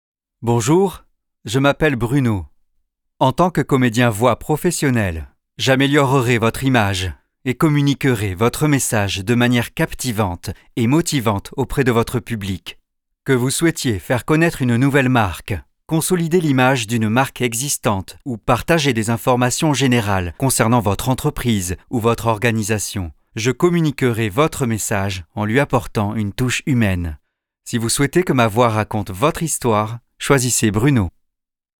Voix off
Marketing Speach
Je possède mon propre Studio d'Enregistrement et pour ma voix j'utilise principalement un Neumann TLM103 et un convertisseur Antelope Orion.
30 - 55 ans - Ténor